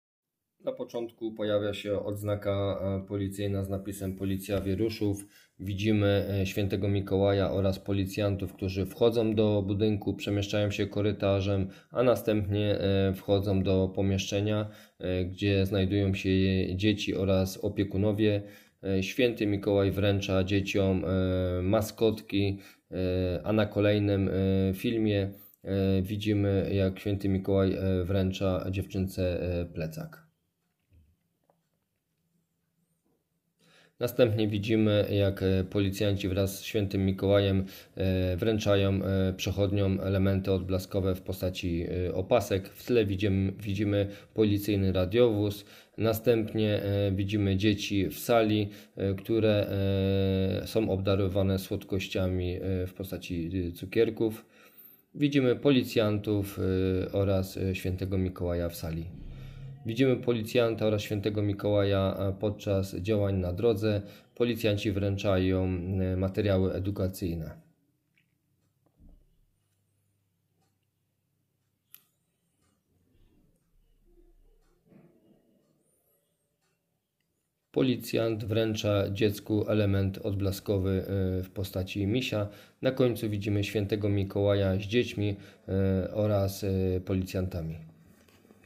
Wykorzystano utwór na licencji Universal Production Music.